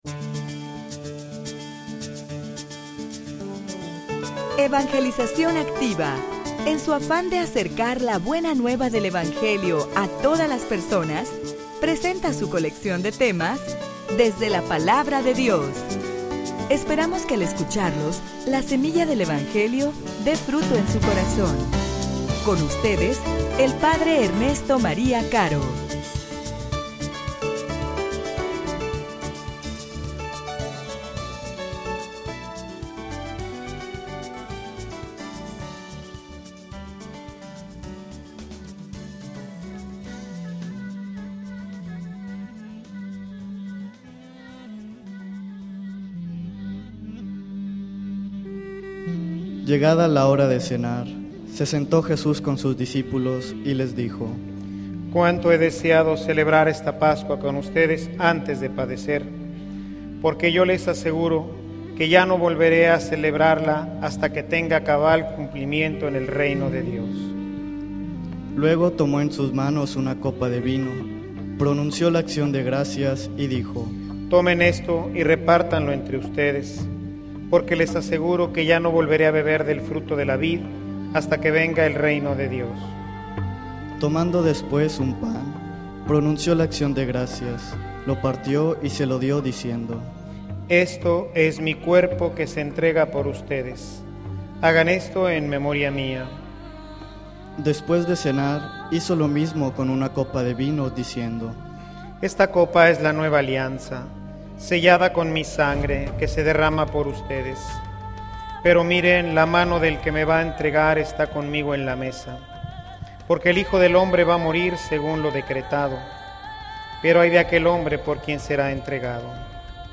homilia_Fieles_hasta_el_martirio.mp3